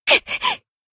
BA_V_Aru_Battle_Damage_2.ogg